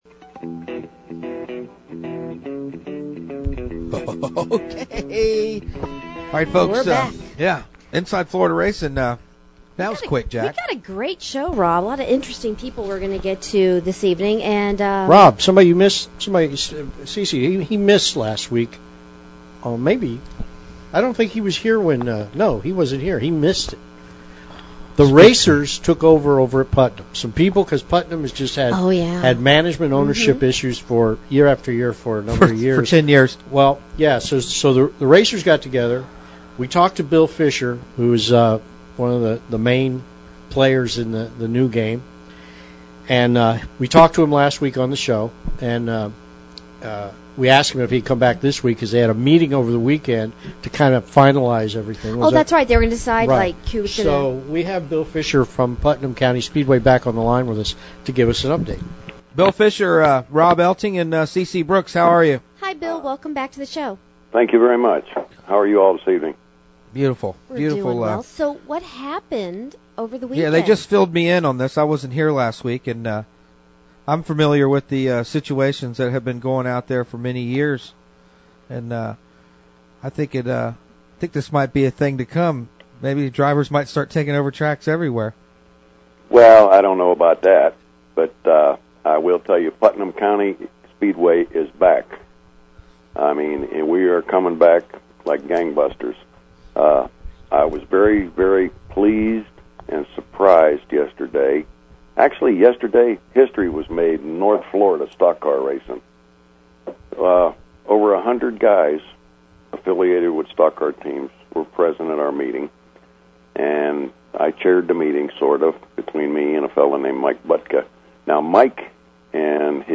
IFR is a positive show with news from around Florida racing and interviews with the promoters, drivers, fans and others who make up the Florida stock car racing community.